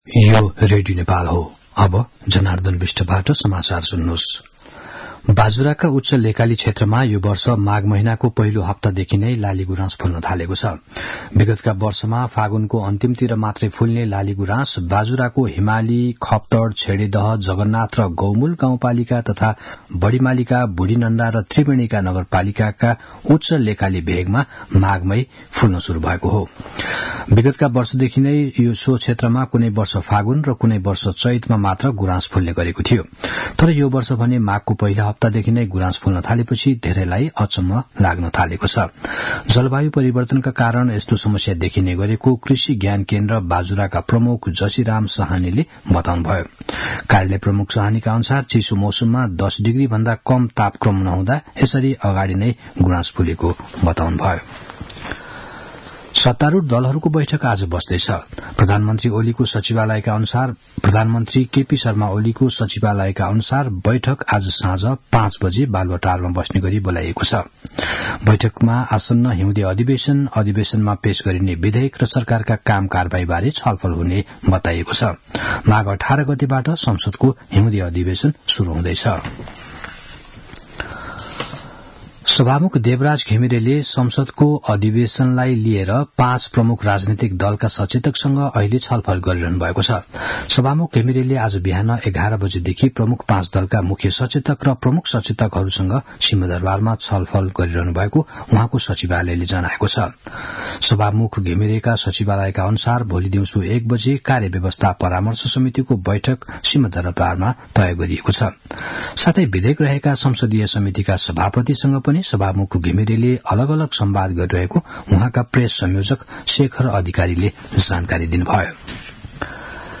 मध्यान्ह १२ बजेको नेपाली समाचार : १५ माघ , २०८१